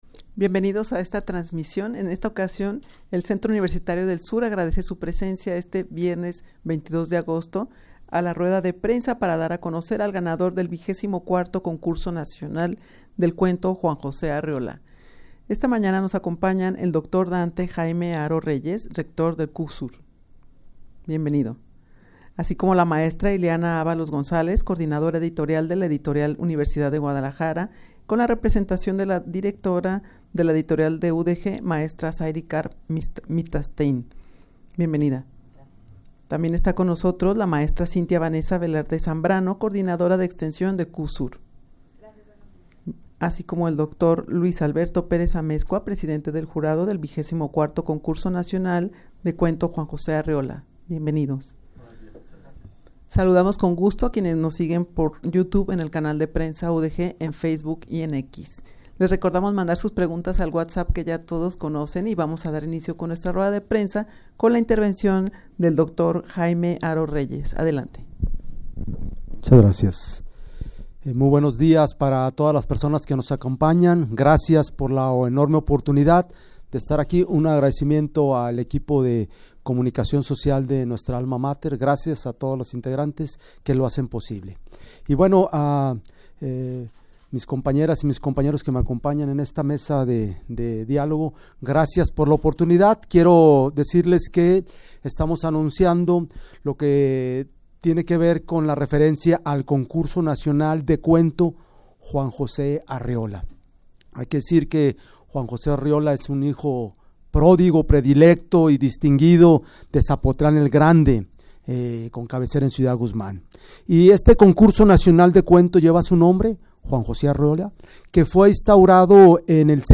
Audio de la Rueda de Prensa
rueda-de-prensa-para-dar-a-conocer-al-ganador-del-xxiv-concurso-nacional-de-cuento-juan-jose-arreola.mp3